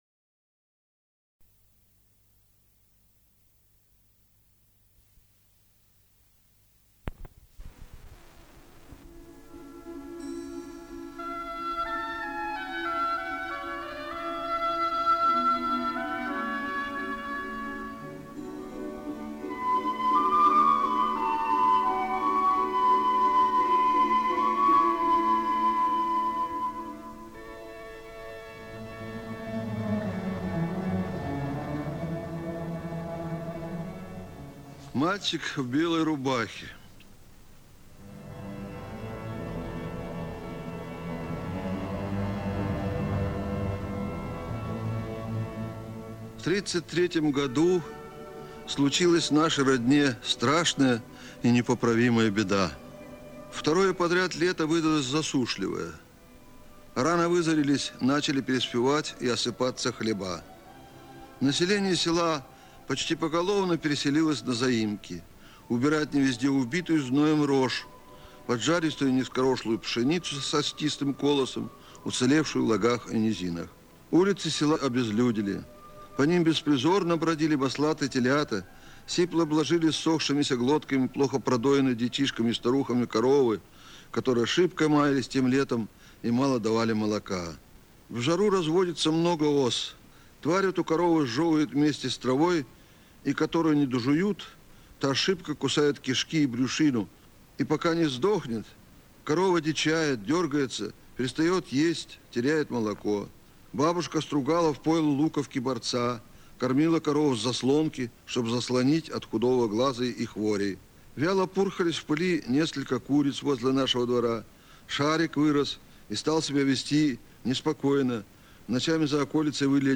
Голос Астафьева. Виктор Петрович читает свои произведения
Отметим, что некоторые записи были сделаны на краевом радио, часть - оцифрованы в краевом учреждении "Красноярский кинограф" ("Енисей Кино"). С этим связан такой технический момент: в начале некоторых аудиозаписей выдерживается пауза примерно на полминуты, а дальше начинается собственно чтение рассказа.